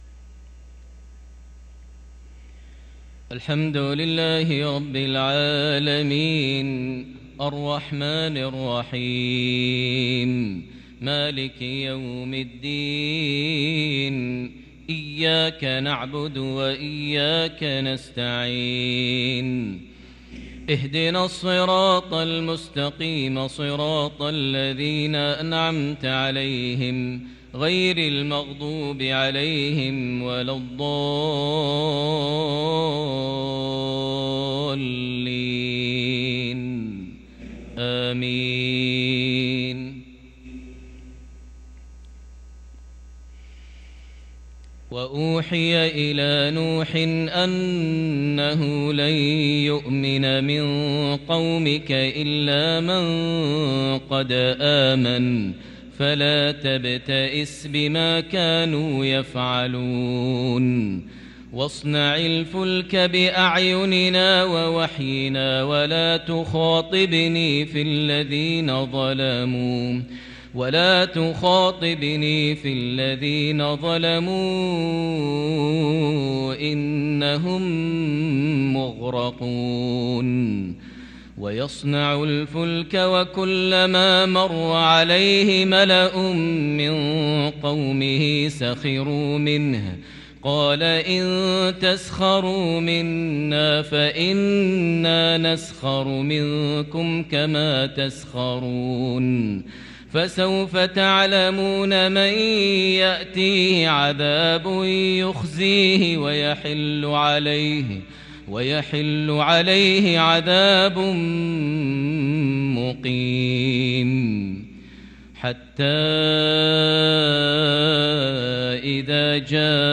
صلاة العشاء للقارئ ماهر المعيقلي 13 صفر 1444 هـ